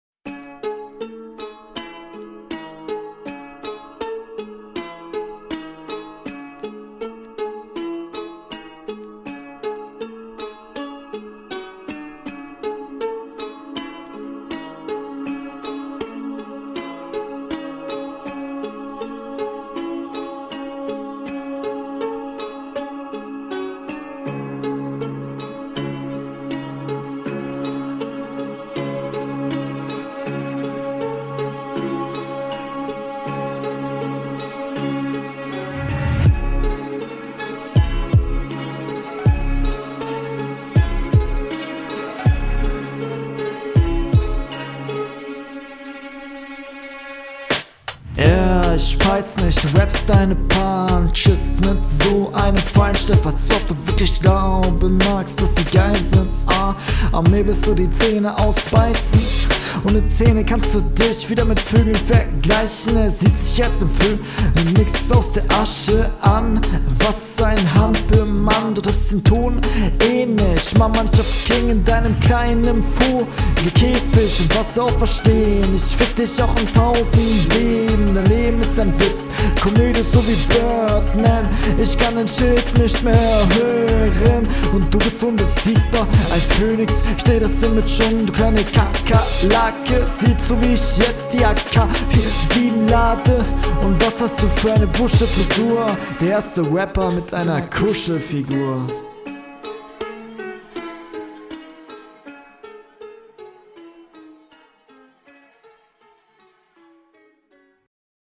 Flowlich noch ziemlich unrutiniert, textlich hast du oft doppelreime was ich gut finde, stimmeimsatz ist …
Ich mag deine Stimme!